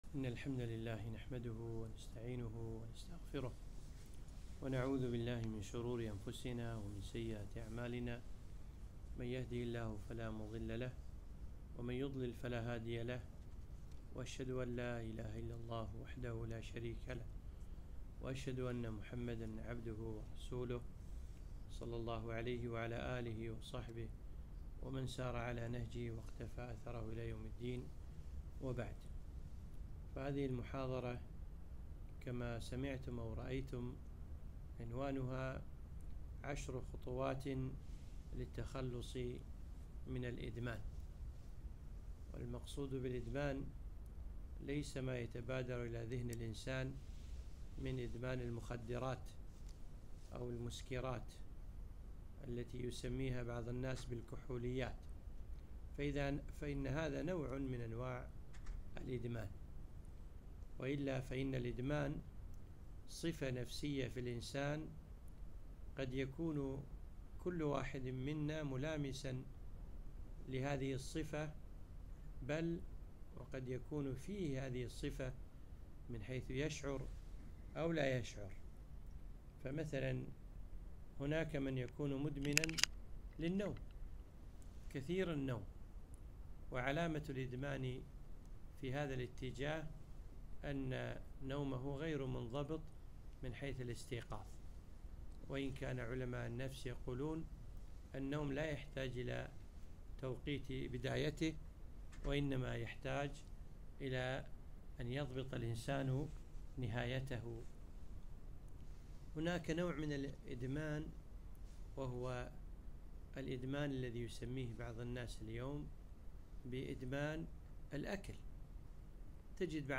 محاضرة قيمة - عشر خطوات لترك الإدمان